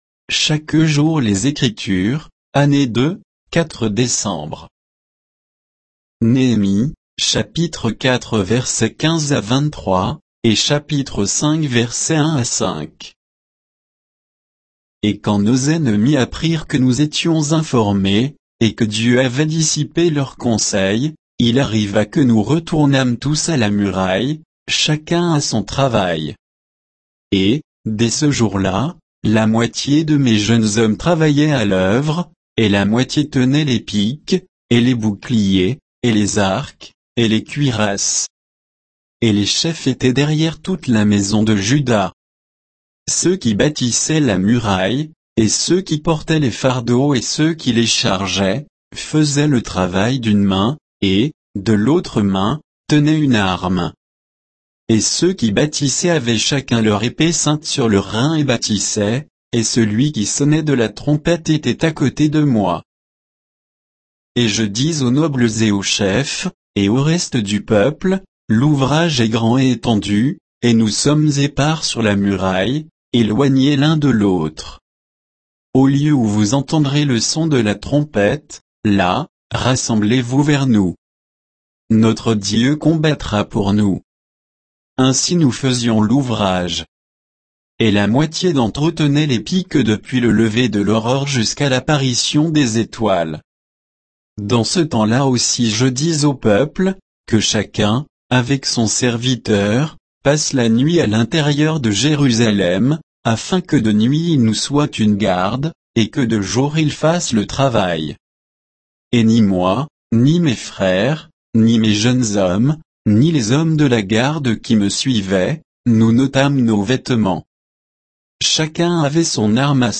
Méditation quoditienne de Chaque jour les Écritures sur Néhémie 4